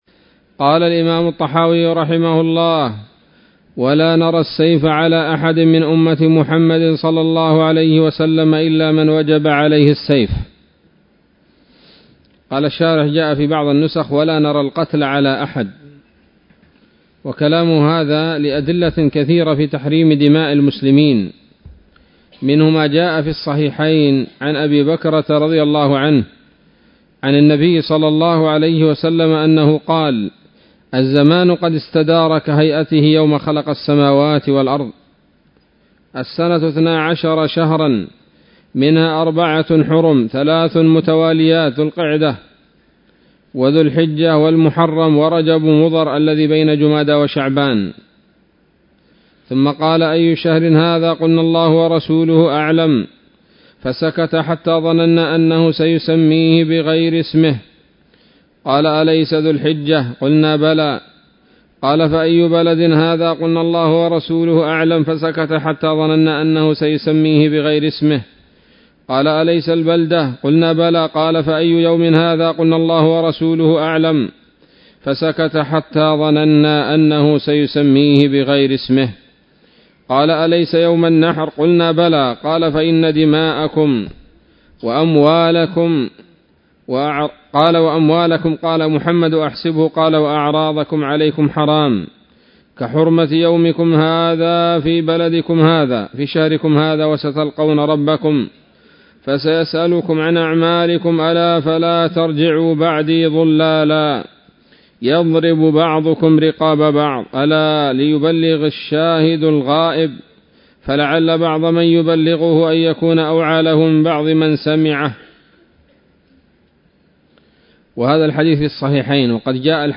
الدرس الرابع والتسعون